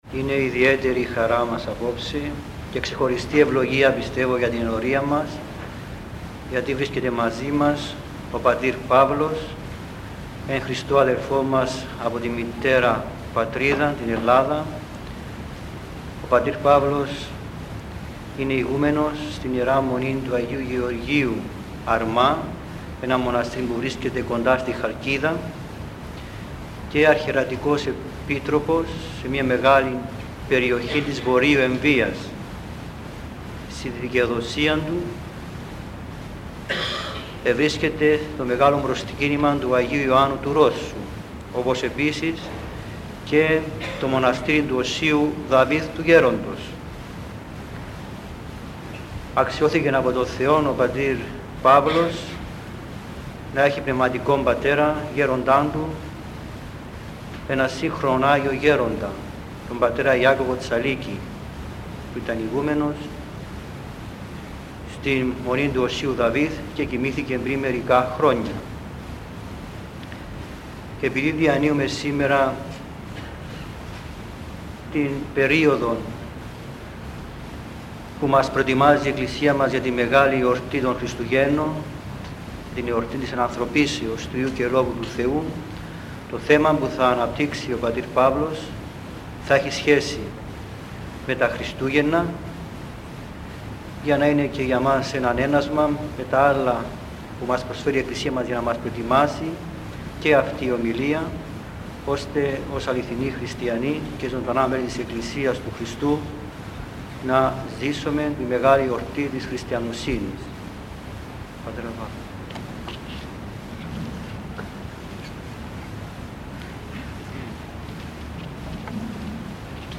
Η Ενανθρώπηση του Χριστού – ομιλία του Μητροπ. Σισανίου και Σιατίστης Παύλου (αρχείο mp3).
Ακολούθως παραθέτουμε ομιλία του νυν Μητροπολίτου Σισανίου και Σιατίστης, Παύλου Ιωάννου, με θέμα: Η Ενανθρώπηση του Χριστού. Η ομιλία αυτή εκφωνήθηκε στην Κύπρο, πριν ο Π. Παύλος Ιωάννου αναδειχθεί ως μητροπολίτης, το 2006.